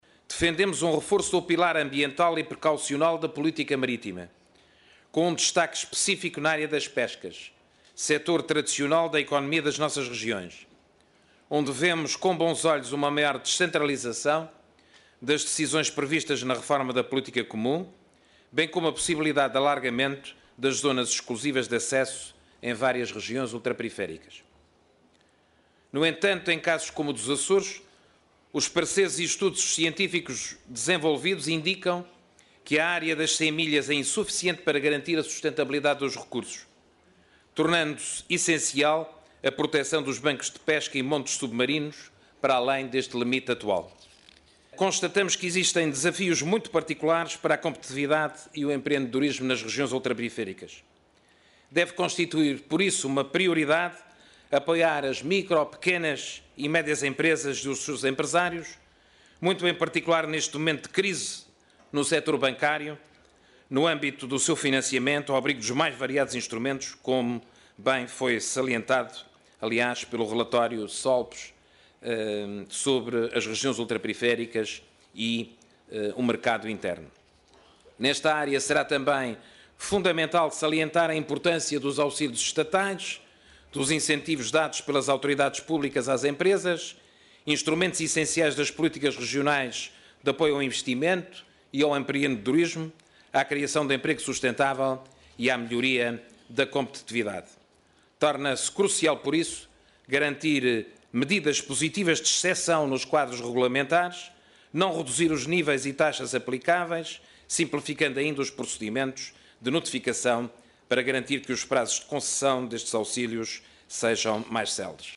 No encerramento do II Fórum das Regiões Ultraperiféricas da União Europeia, que reuniu mais de quinhentas pessoas em Bruxelas, o Presidente do Governo dos Açores e Presidente da Conferência de Presidentes das RUP sintetizou os dois dias de debate lembrando que o mar, a agricultura e a situação das pequenas e médias empresas continuam a ser as maiores preocupações.